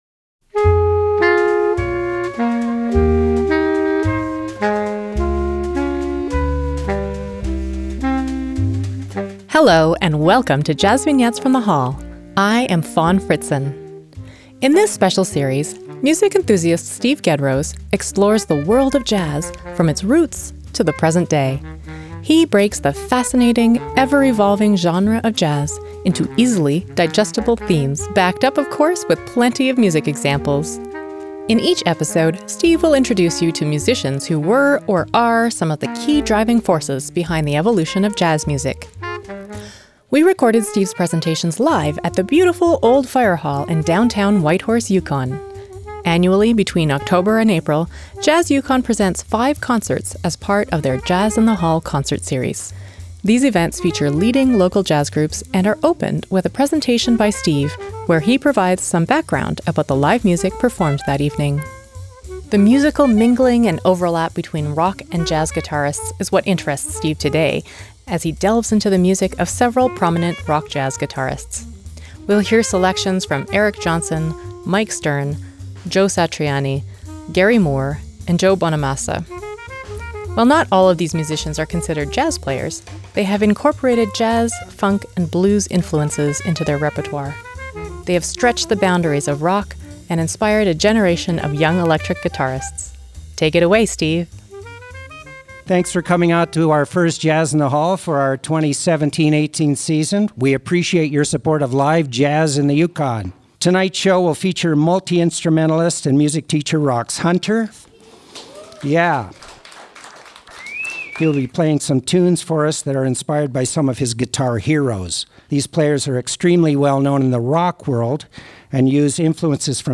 JVFTH_30_-_Rock_Jazz_Guitarists.mp3 57,846k 256kbps Stereo Comments